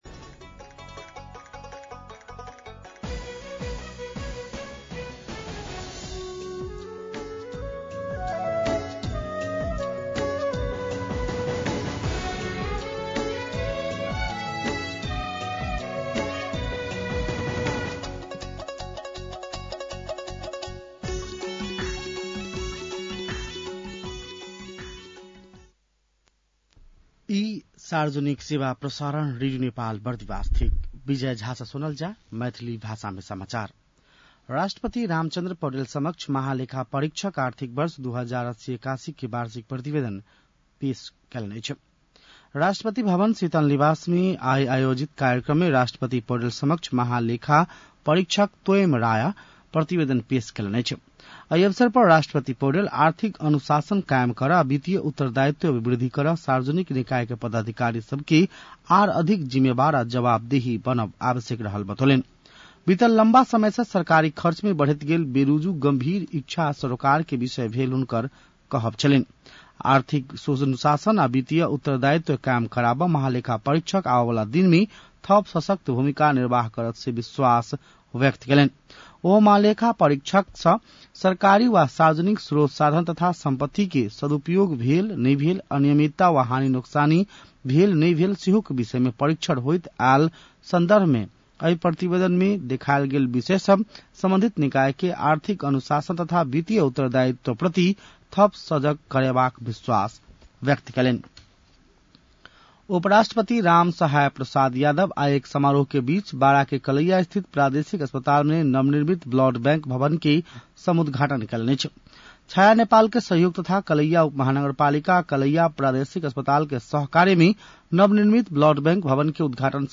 मैथिली भाषामा समाचार : ३१ वैशाख , २०८२